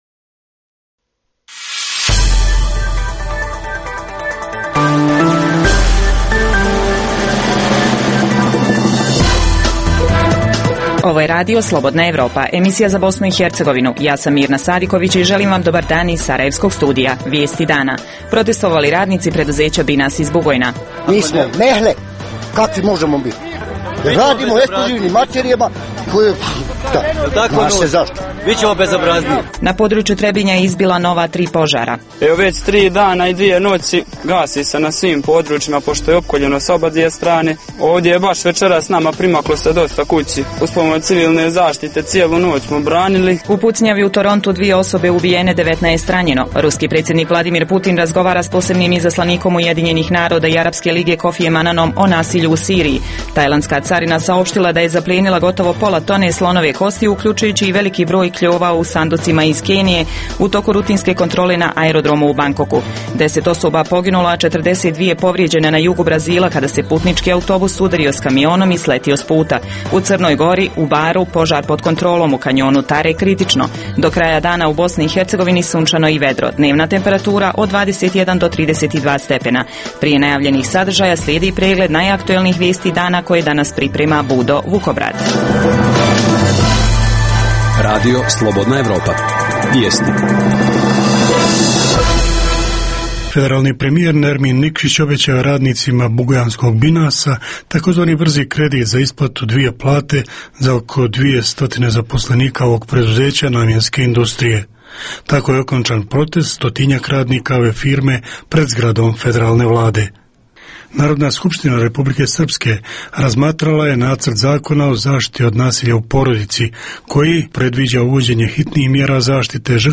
Emisija namijenjena slušaocima u Bosni i Hercegovini.